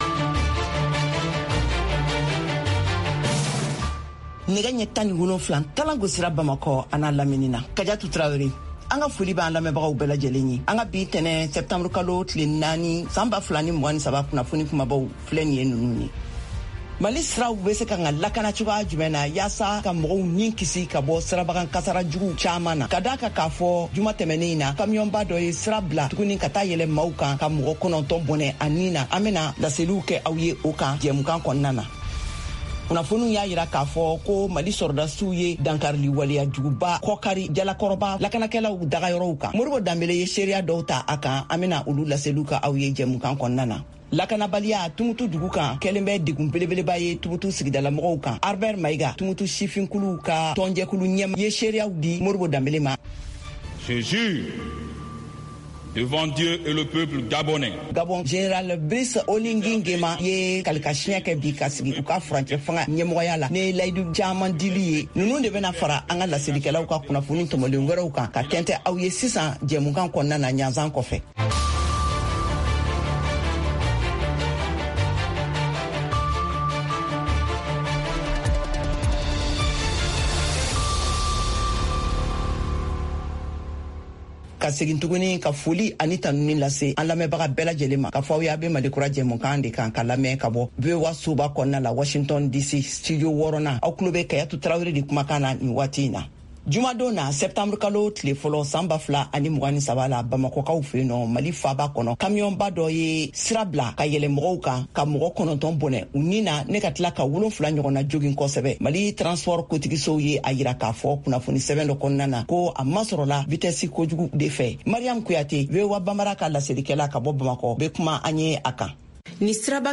10 min Newscast